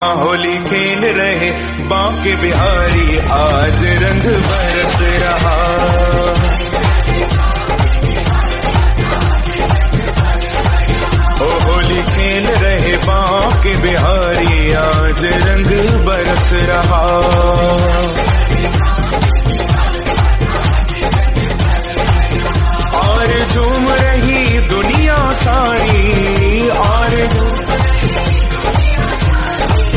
Bhakti Ringtones